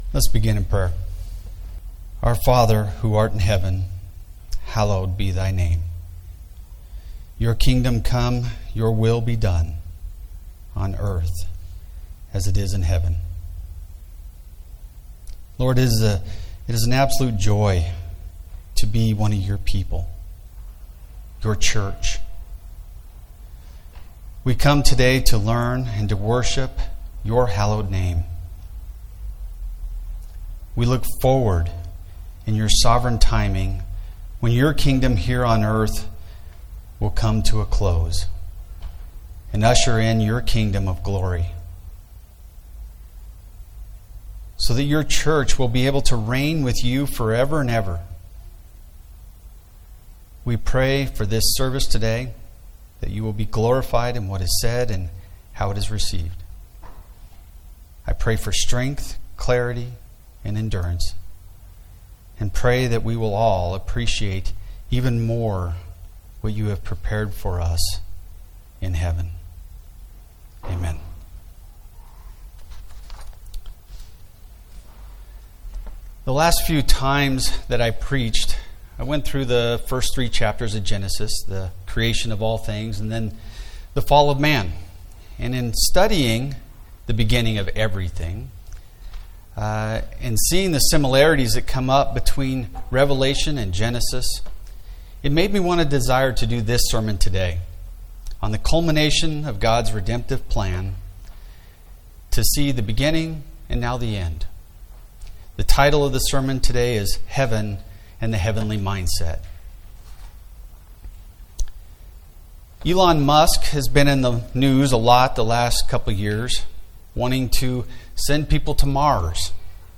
The message on Sunday